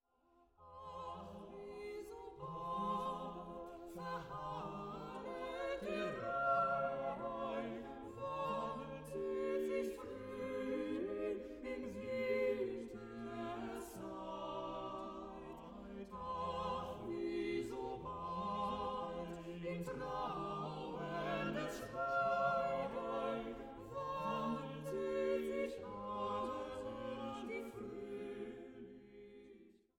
weltliche Werke von Komponisten des 19. und 20. Jahrhunderts
kristallklar aufgenommen im Gewandhaus zu Leipzig